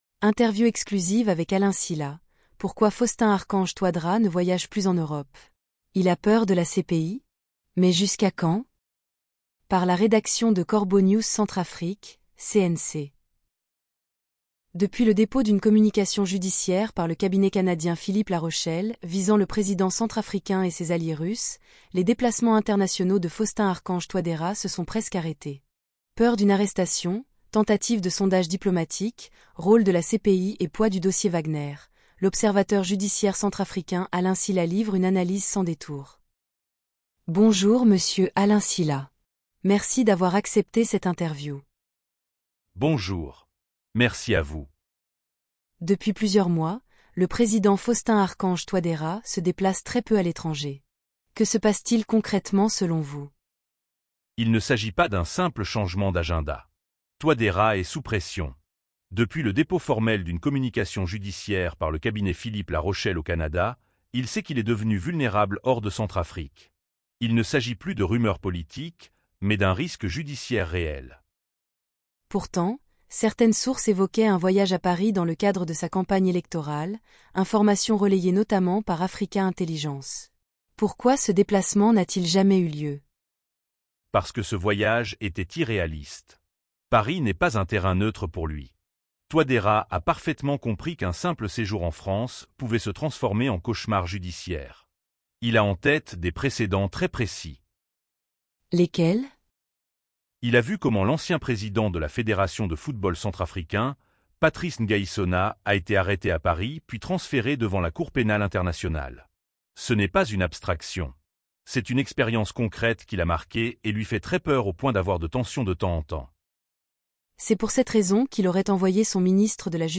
Interview exclusive